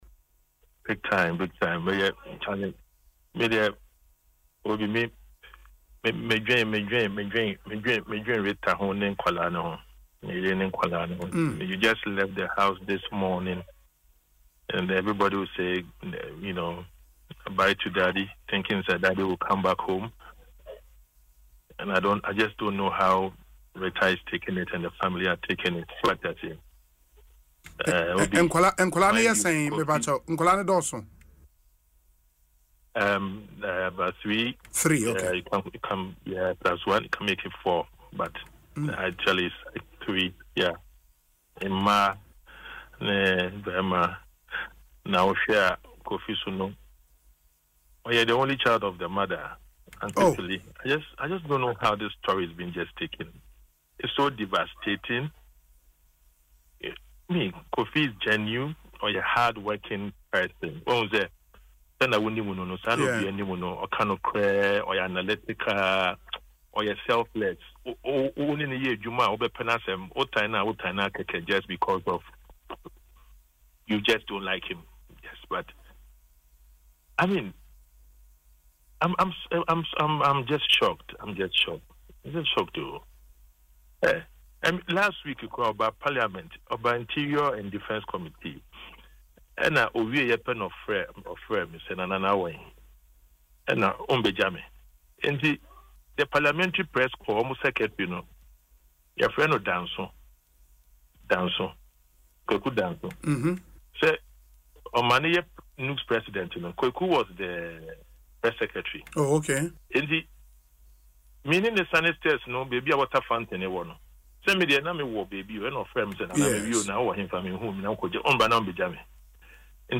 In an emotional interview on Asempa FM’s Ekosii Sen, Dr. Afriyie, who is a cousin of the late minister, revealed he was the only son of his mother—a development he says makes the loss even more painful.
“You just left the house this morning, and everybody was saying, you know, ‘bye to Daddy,’ thinking that Daddy will come back home,” he said, his voice cracking.